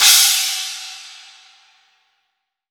• Roomy Crash Cymbal Sound Sample E Key 01.wav
Royality free crash sound sample tuned to the E note. Loudest frequency: 6017Hz
roomy-crash-cymbal-sound-sample-e-key-01-b4x.wav